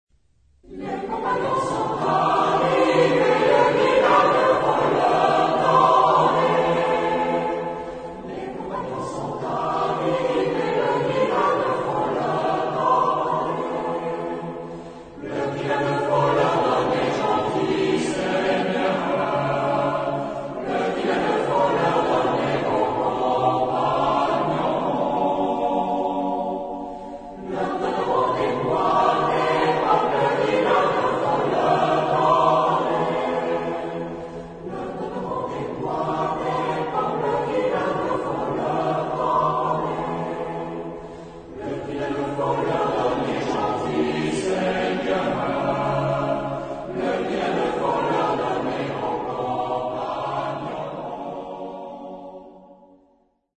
Chant de quête
Genre-Style-Form: Popular ; Traditional ; Partsong ; Secular
Type of Choir: SATB  (4 mixed voices )
Origin: Upper Limousin (F)